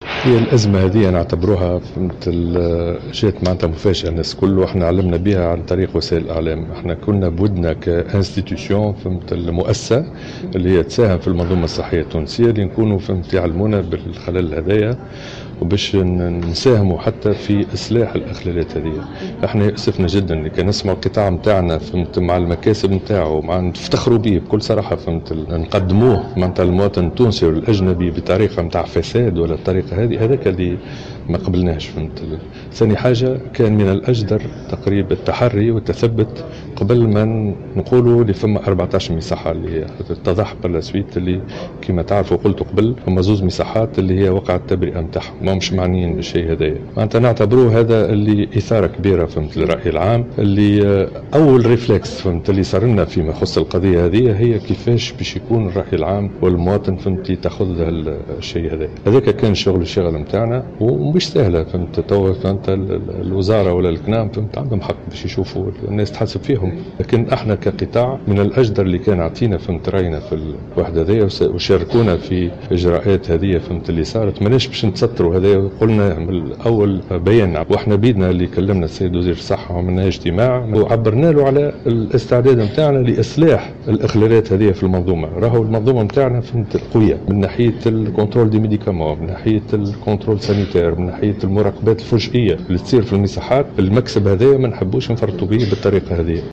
ونظمت الغرفة النقابية الوطنية للمصحات الخاصة صباح اليوم ندوة صحفية بمقر الاتحاد التونسي للصناعة و التجارة و الصناعات التقليدية بخصوص اللوالب القلبية المنتهية الصلوحية لابراز موقفها للرأي العام لما اعتبرته ضجة اعلامية مستهدفة أضرت بالقطاع.